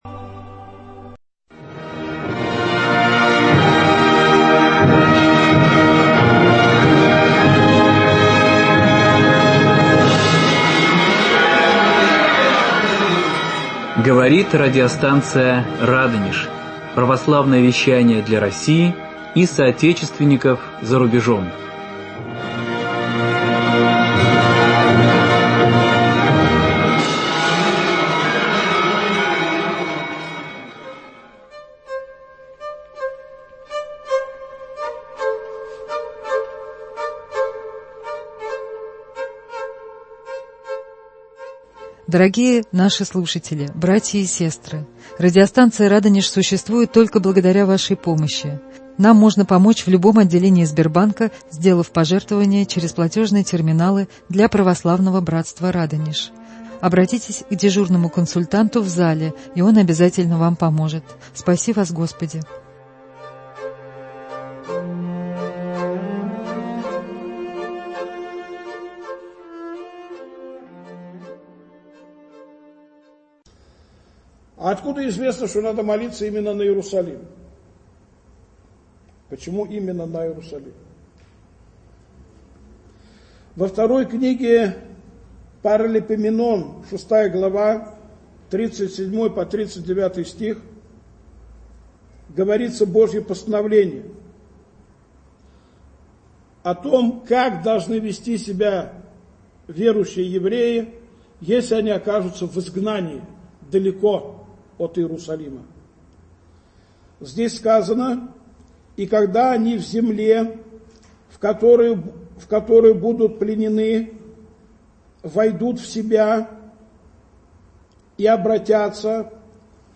Беседы на книгу пророка Даниила.